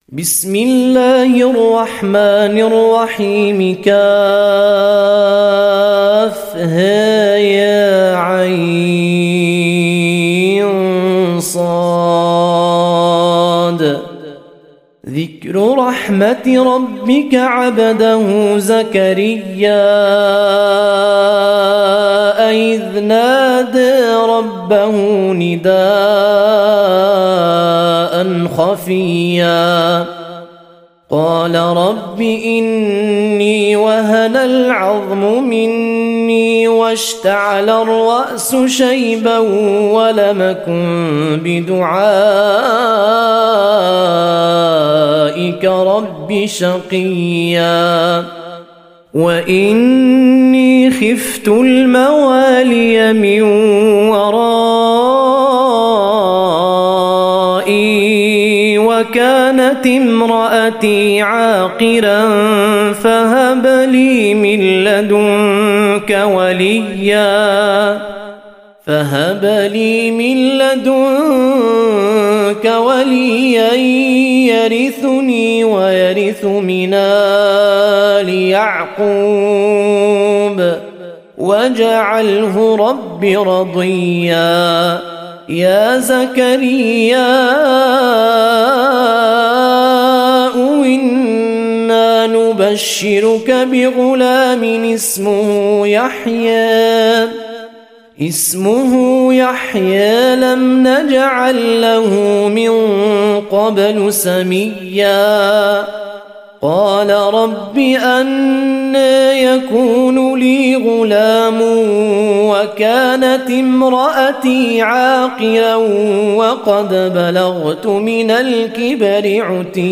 EsinIslam Audio Quran Recitations Tajweed, Tarteel And Taaleem.